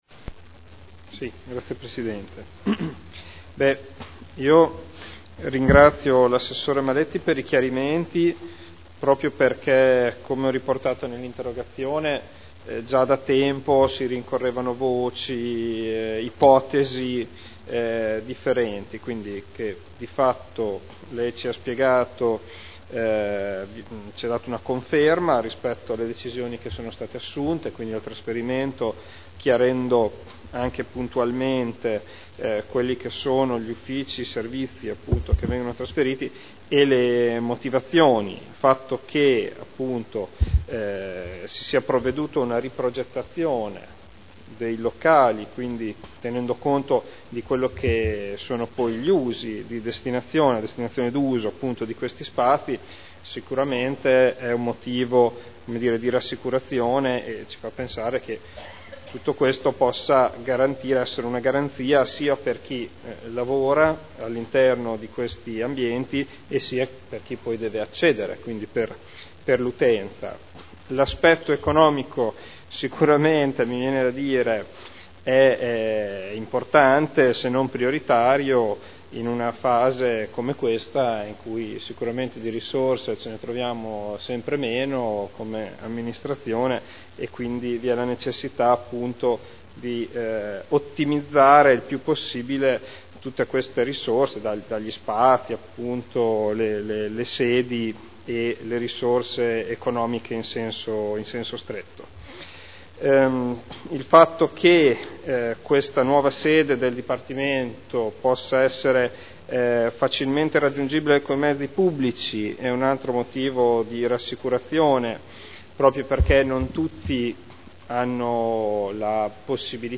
Seduta del 24/02/2011. Replica a risposta dell'Assessore Maletti su interrogazione consigliere Ricci (Sinistra per Modena) avente per oggetto: “Sede DSP Ausl” (presentata il 12 ottobre 2010 – in trattazione il 24.2.2011)